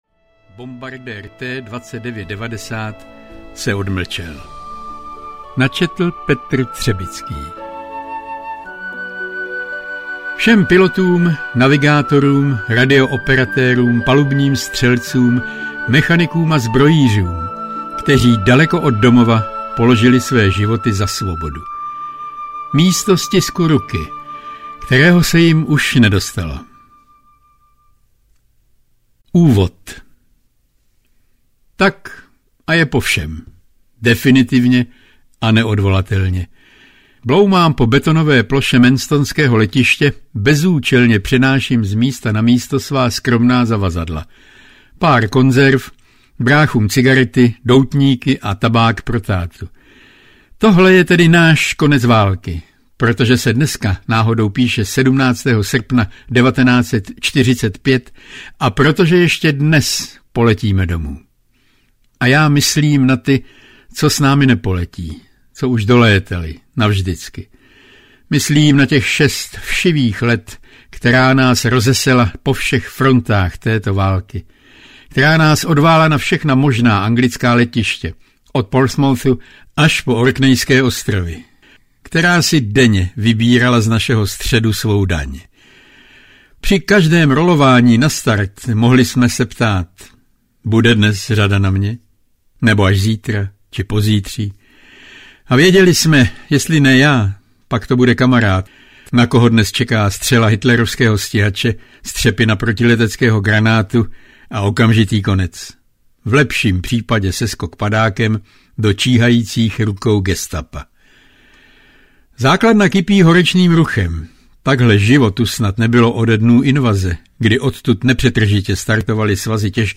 Bombardér T-2990 se odmlčel audiokniha
Ukázka z knihy